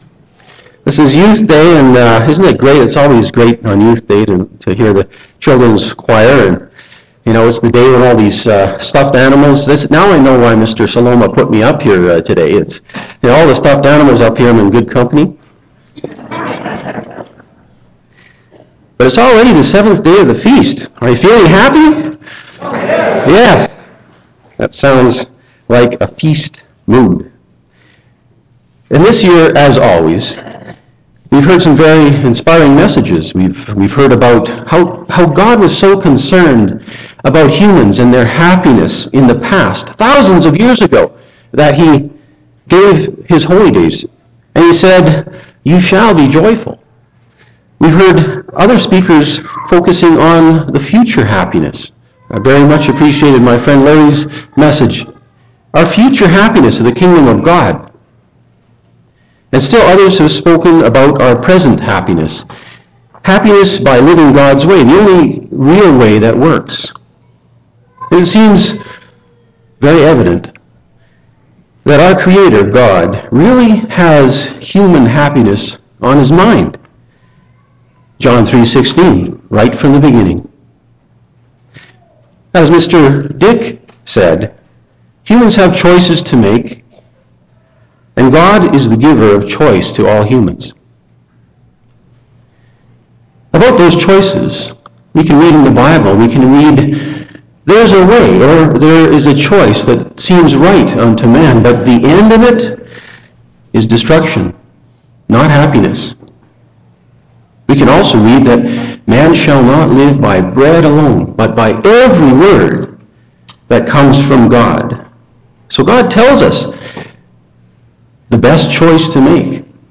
This sermon was given at the Canmore, Alberta 2012 Feast site.